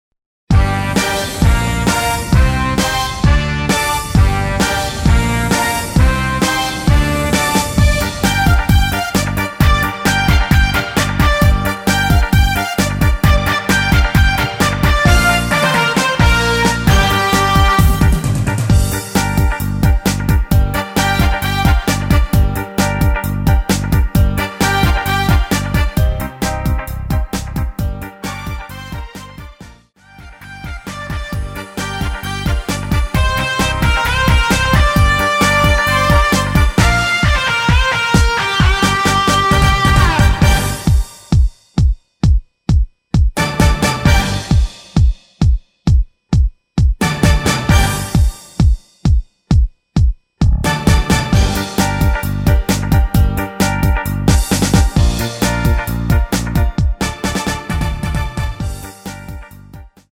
디스코로 새롭게 편곡 하였습니다. 대부분의 여성분이 부르실수 있는키로 제작 하였습니다.